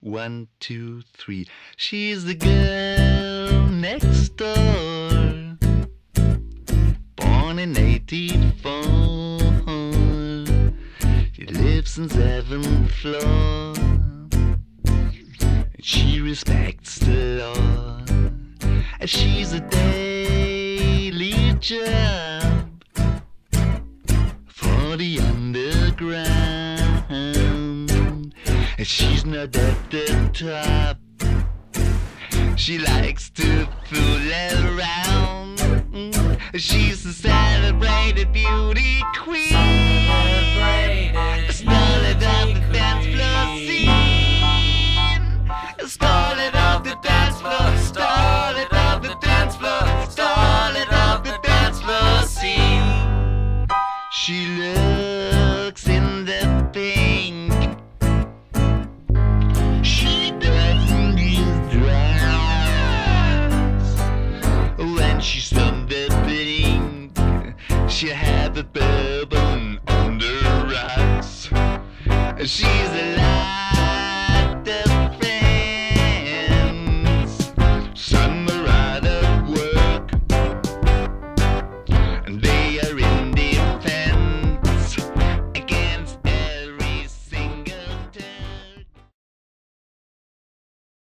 voc., sample drums, electronics
choir